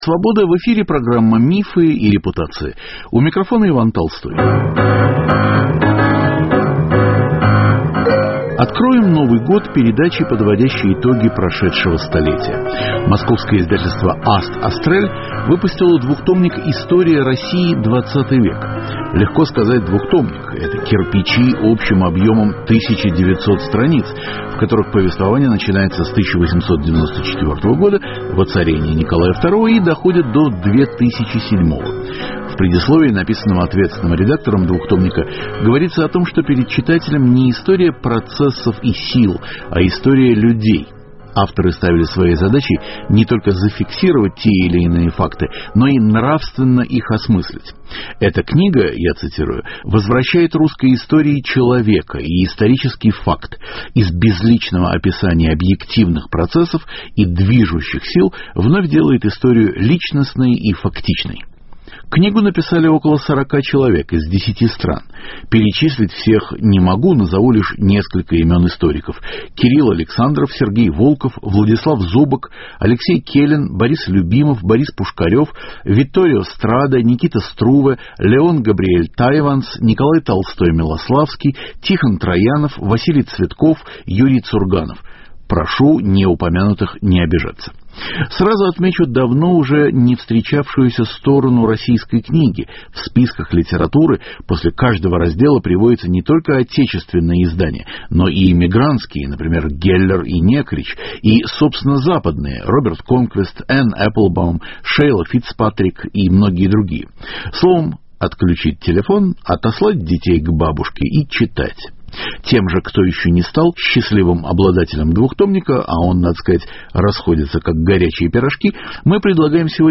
Какие российские мифы развенчивает этот труд? Какие репутации выдерживают пересмотр? Гость студии – ответственный редактор издания Андрей Зубов.